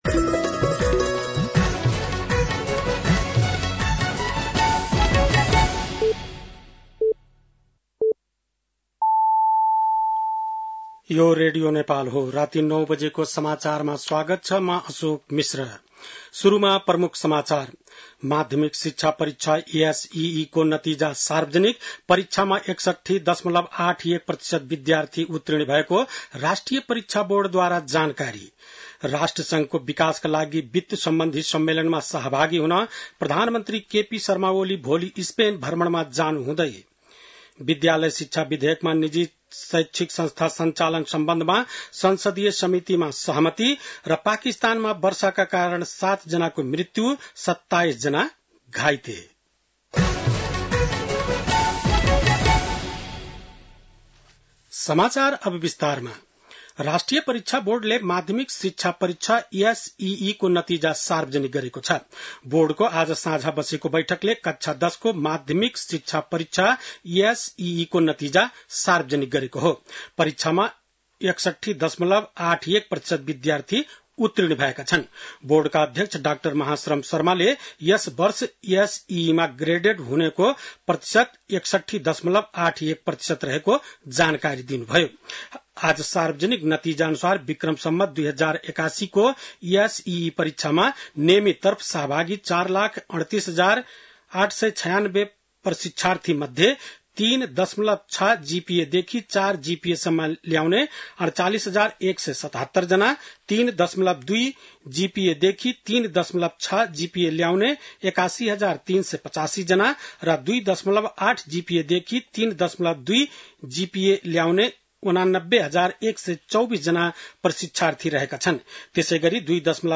बेलुकी ९ बजेको नेपाली समाचार : १३ असार , २०८२